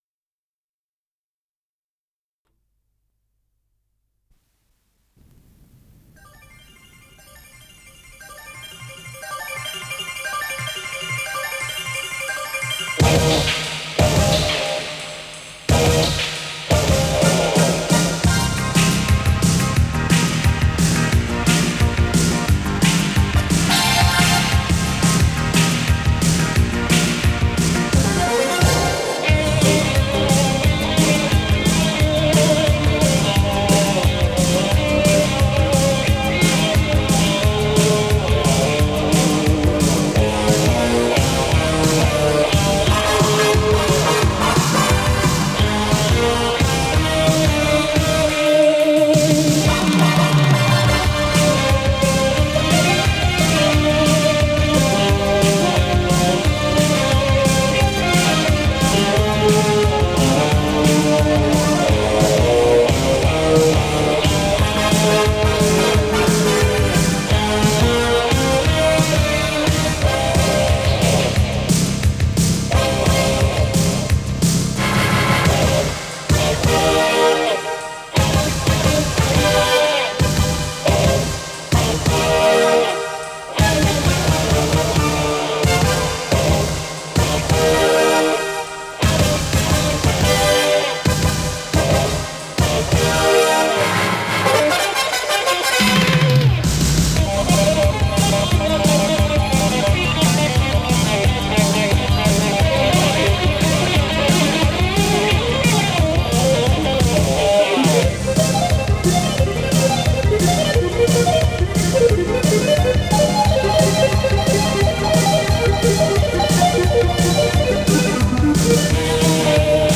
с профессиональной магнитной ленты
Скорость ленты38 см/с
Тип лентыORWO Typ 106